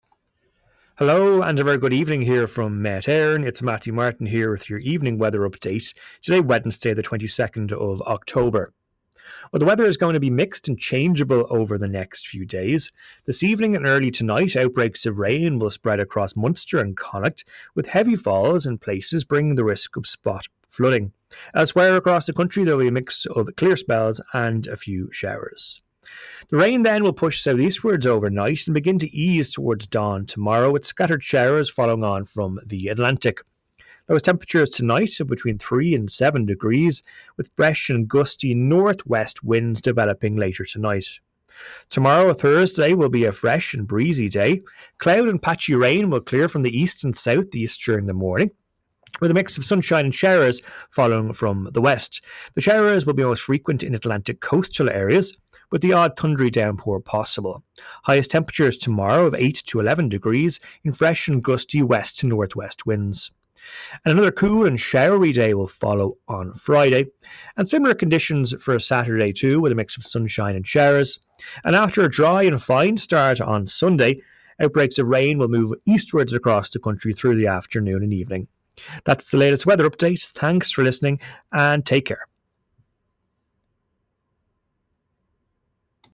Weather Forecast from Met Éireann / Ireland's Weather 5pm Wednesday 22 October 2025